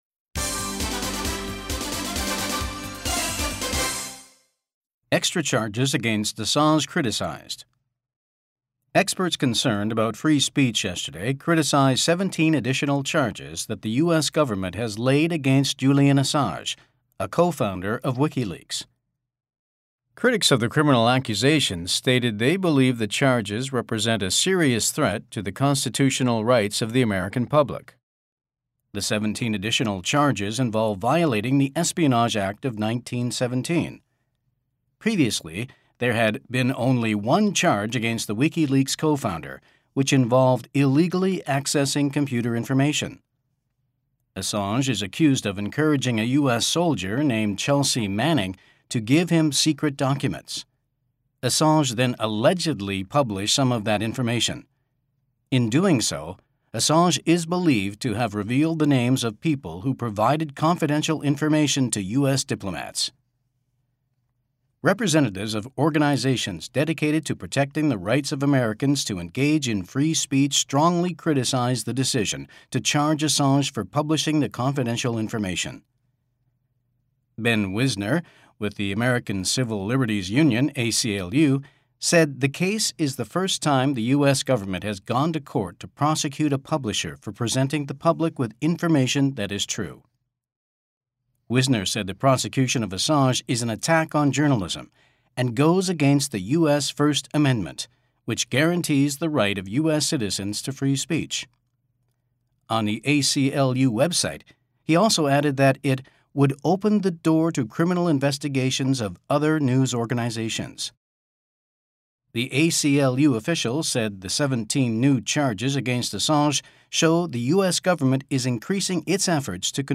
特聘“ICRT”外籍專業新聞播報員錄製音檔，分為正常版及慢速版，反覆練習更能輕鬆聽懂！